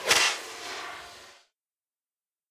sfx_fly.wav